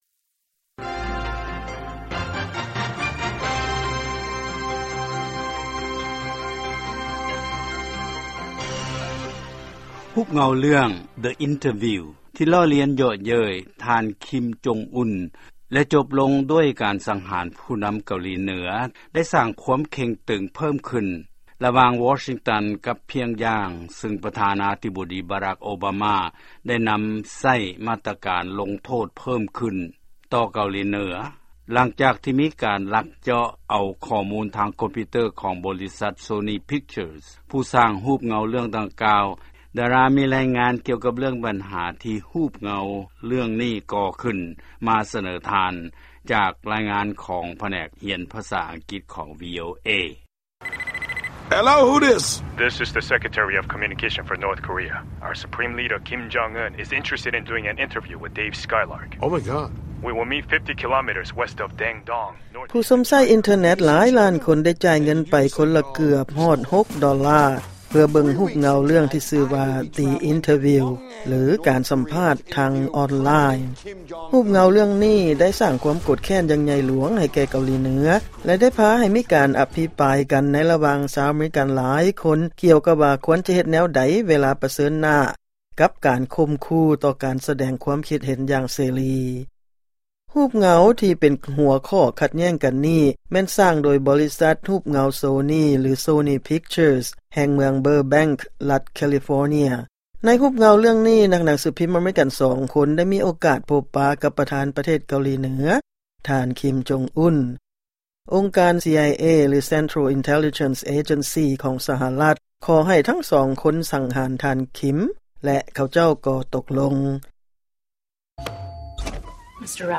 ຟັງລາຍງານນີ້ ເປັນພາສາອັງກິດຊ້າໆ: